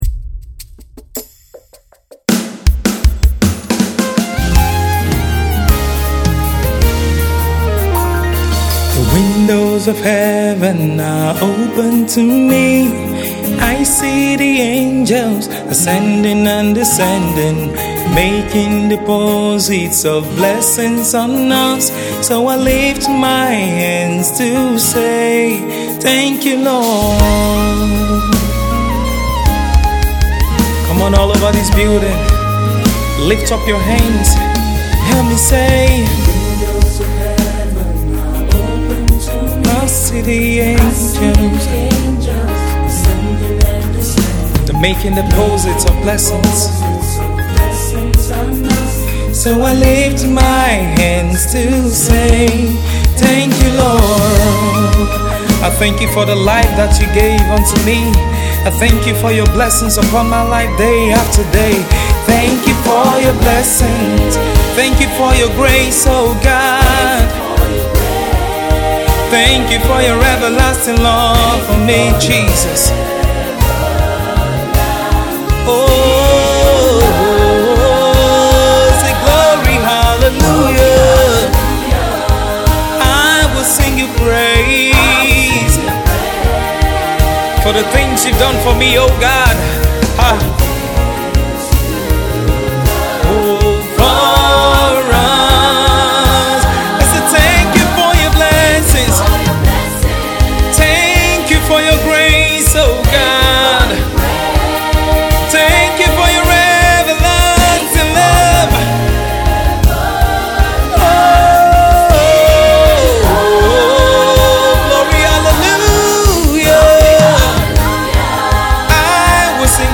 soul stirring song of thanksgiving